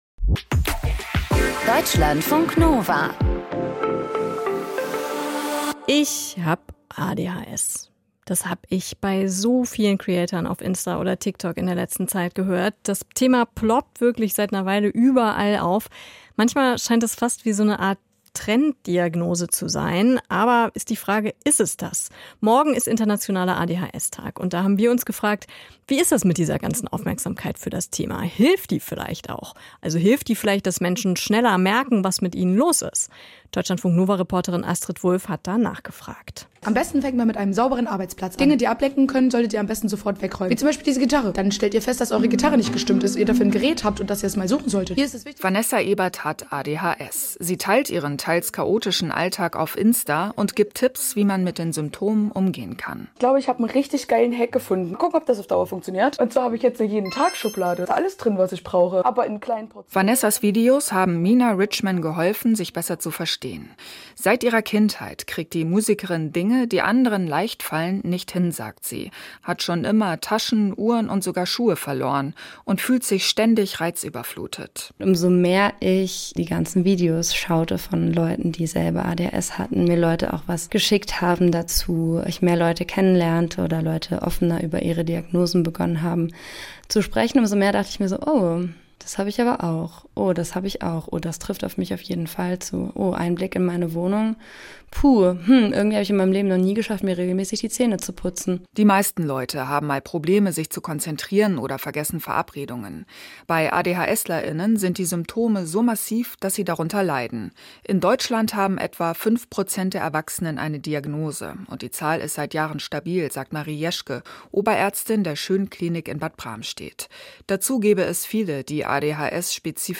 Das Interview im Deutschlandfunk Kultur greift kulturelle und politische Trends ebenso auf wie... Mehr anzeigen